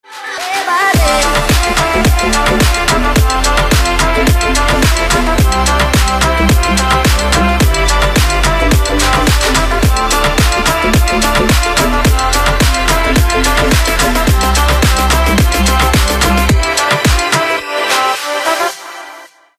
• Качество: 320, Stereo
громкие
женский вокал
Electronic
EDM
электронная музыка
club
house